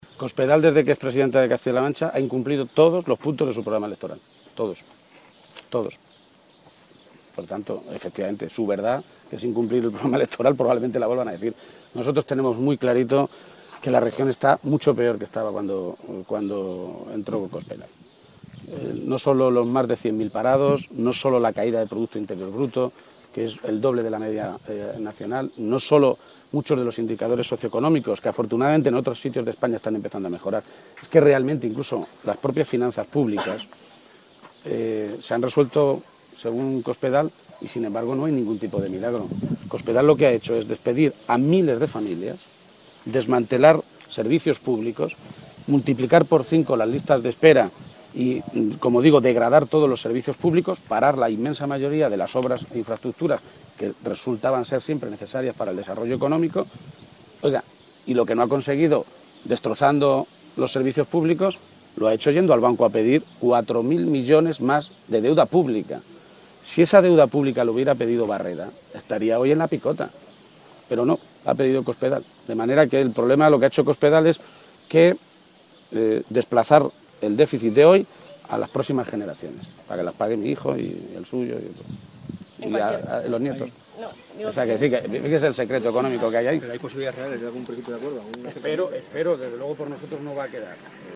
Se pronunciaba de esta manera García-Page esta mañana, en Toledo, a preguntas de los medios de comunicación, en relación a la reunión que mantendrán el próximo lunes el consejero de Hacienda del Gobierno regional, Arturo Romaní, y el portavoz del grupo socialista en las Cortes de Castilla-La Mancha, José Luis Martínez Guijarro.
Cortes de audio de la rueda de prensa